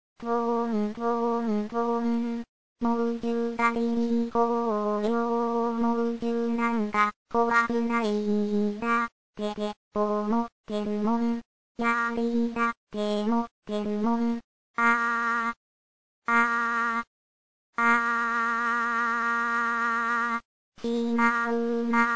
○リーダーの後を追いかけて歌います。